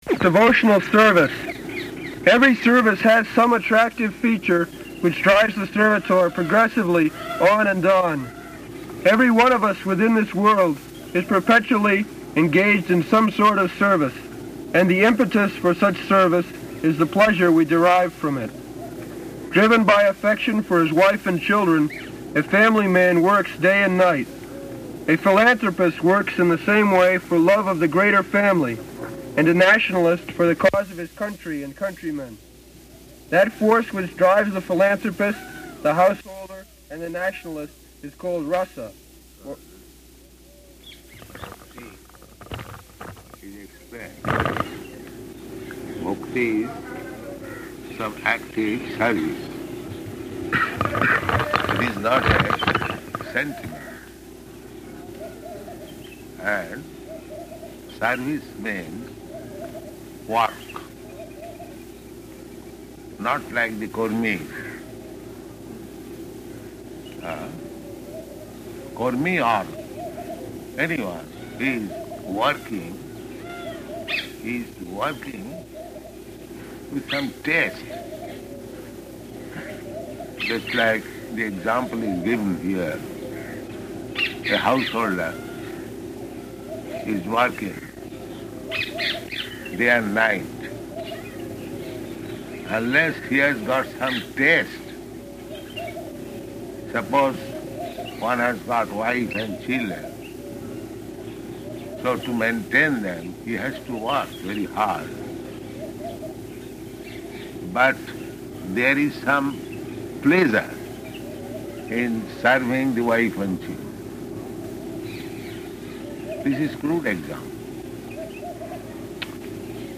October 17th 1972 Location: Vṛndāvana Audio file